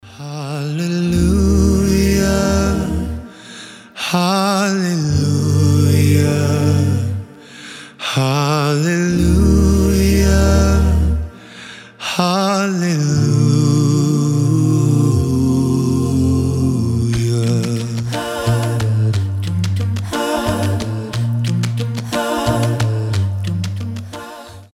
• Качество: 320, Stereo
хор
христианские
красивый вокал
рождественские
акапелла